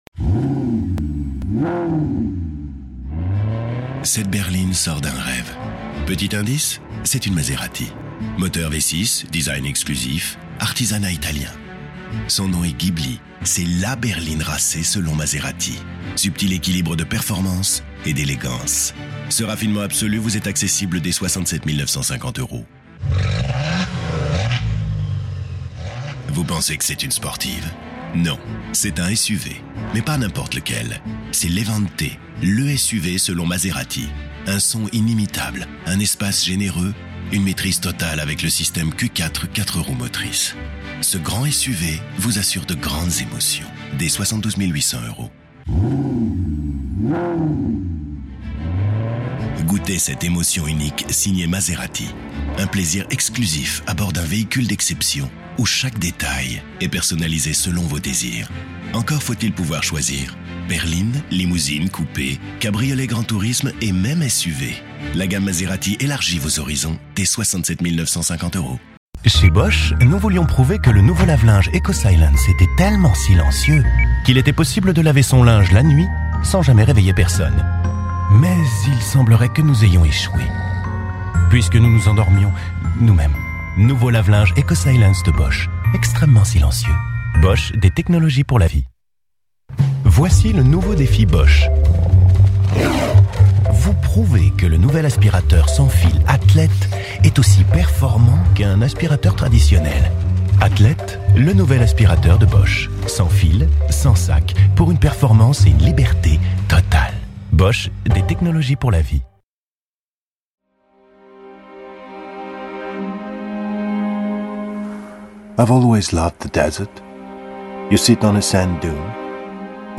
Voicereel